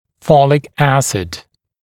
[‘fɔlɪk ‘æsɪd][‘фолик ‘эсид]фолиевая кислота